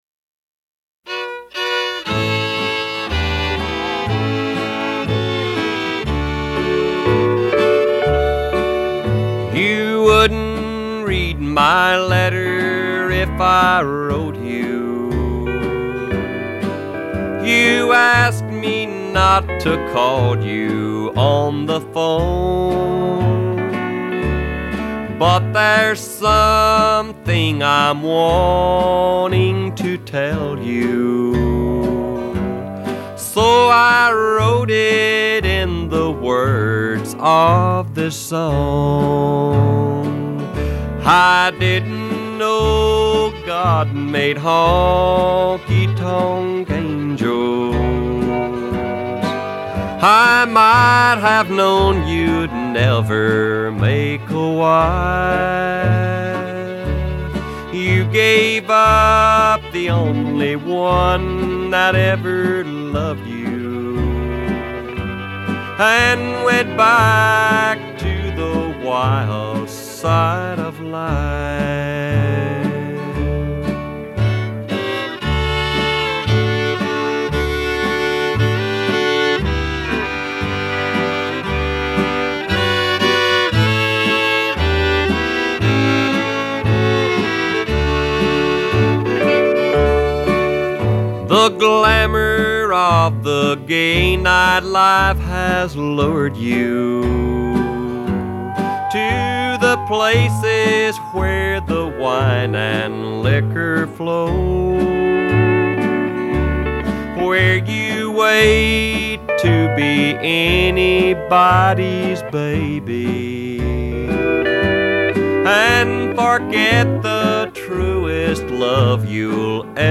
Same instrumentation, just without the solos.